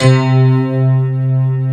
Index of /90_sSampleCDs/USB Soundscan vol.09 - Keyboards Old School [AKAI] 1CD/Partition A/17-FM ELP 5